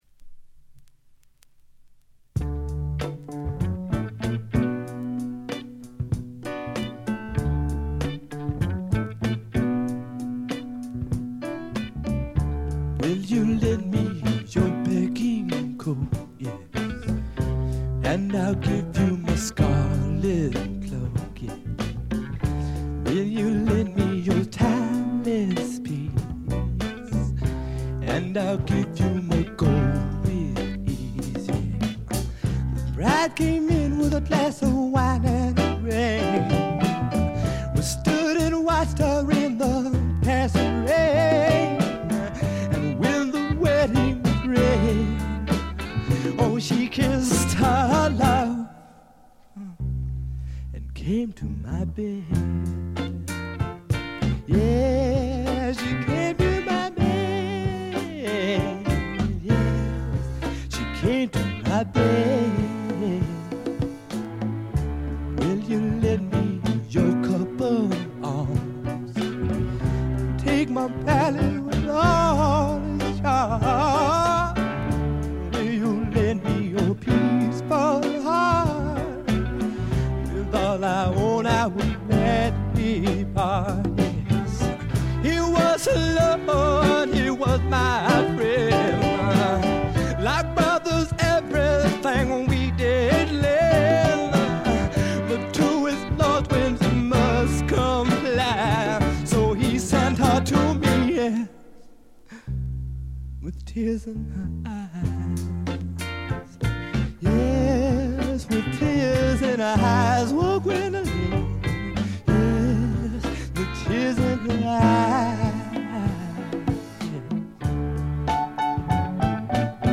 ロックとソウル境界線を行き来する傑作です。
試聴曲は現品からの取り込み音源です。